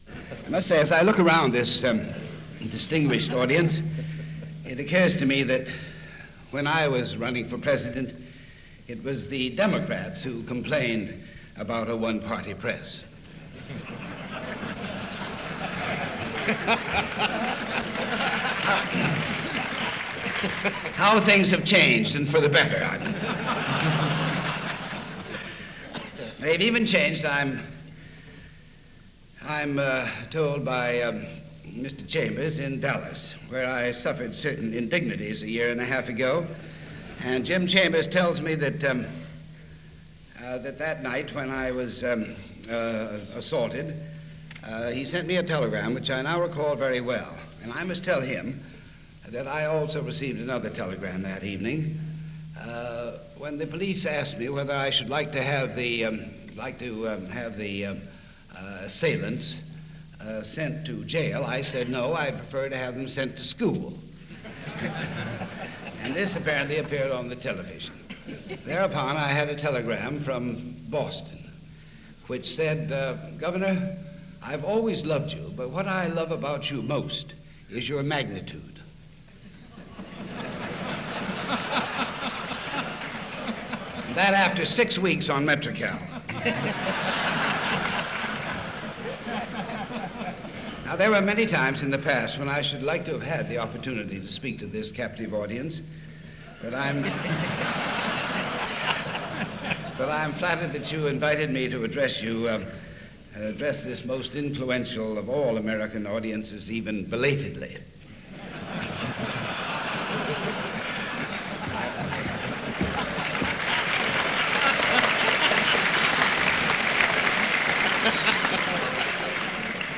Adlai Stevenson Addresses The Convention Of Newspaper Publishers - 1965 - Past Daily Reference Room - delivered in April of 1965,
Adlai-Stevenson-Address-before-Convention-Of-Newspaper-Publishers-April-1965.mp3